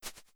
Grass2.wav